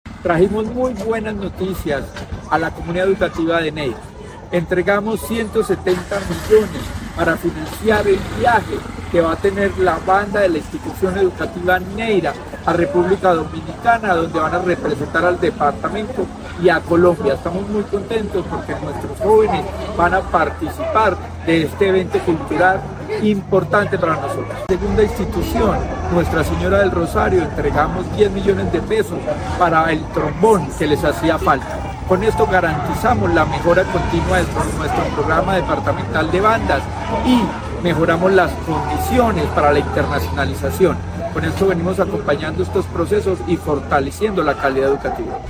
Secretario de Educación de Caldas, Luis Herney Vargas Barrera.
Secretario-de-Educacion-Luis-Herney-Vargas-recursos-bandas-Neira.mp3